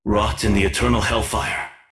The materials I am posting here are the self-made voice effects for my custom trebuchet unit, "Kukulkan Catapult"!
These voices were recorded by myself and produced using Sovits' voice tone replacement.